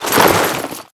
tac_gear_1.ogg